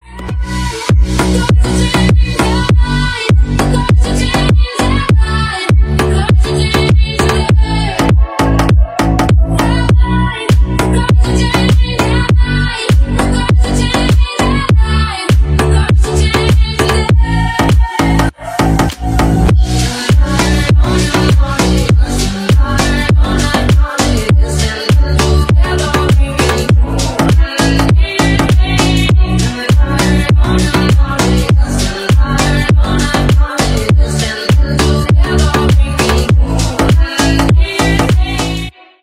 Ремикс
клубные # громкие